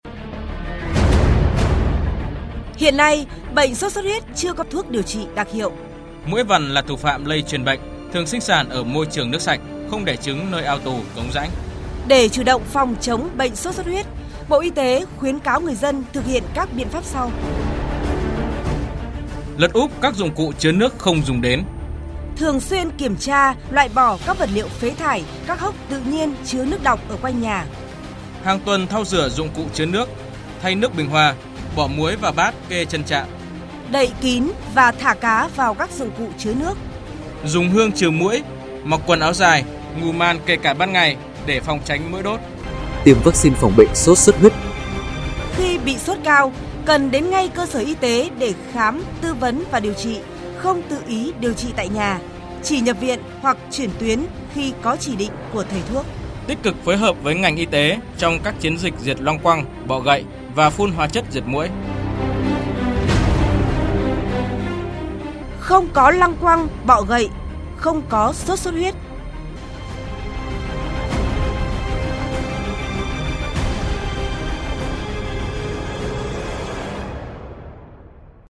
Thông điệp phát thanh phòng chống sốt xuất huyết
Thong-diep-phat-thanh-SXH25.mp3